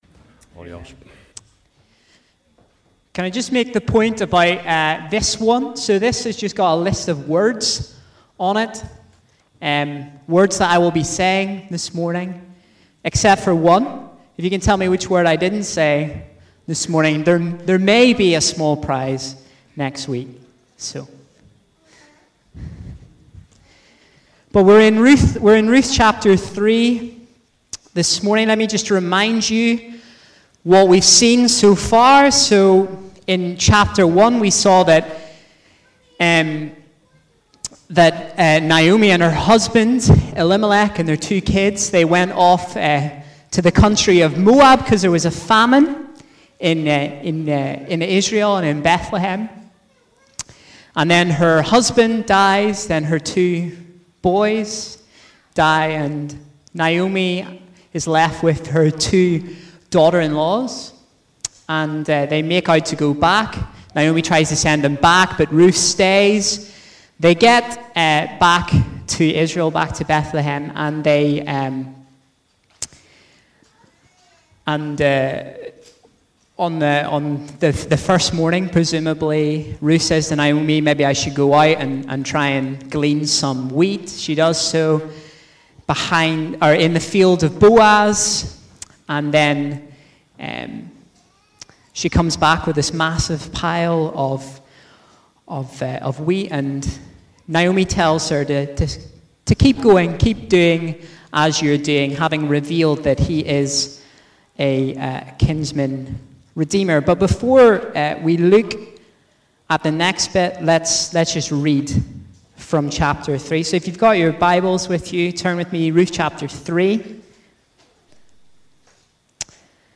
A message from the series "Ruth."